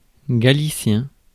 Ääntäminen
Etsitylle sanalle löytyi useampi kirjoitusasu: galicien Galicien Ääntäminen France: IPA: /ɡa.li.sjɛ̃/ Haettu sana löytyi näillä lähdekielillä: ranska Käännöksiä ei löytynyt valitulle kohdekielelle.